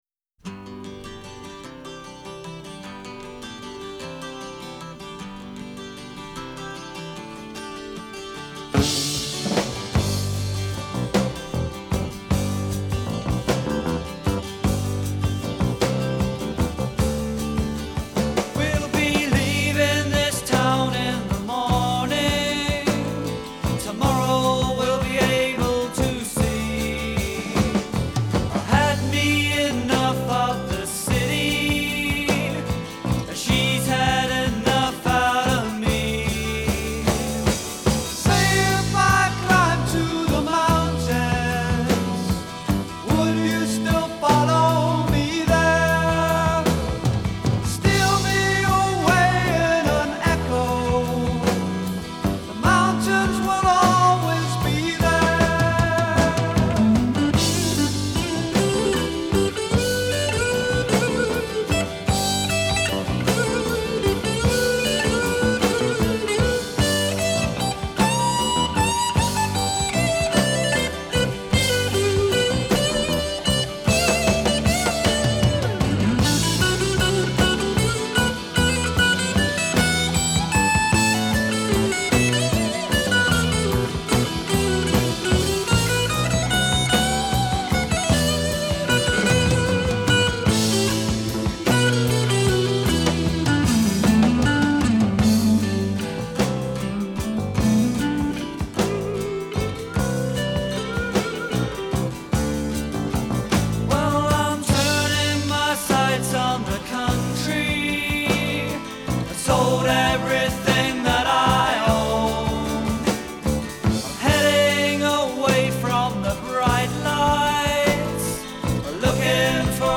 Country: Rock, Hard Rock, Blues Rock